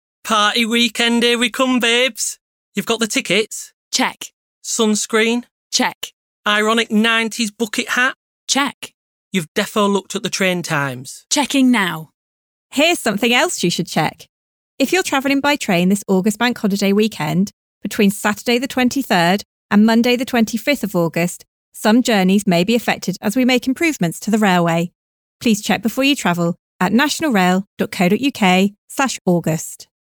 Radio advert